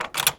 door_lock_close_01.wav